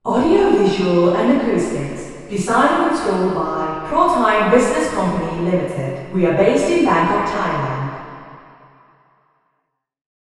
Below are auralizations for the four test positions in the study.
Receiver 04 Female Talker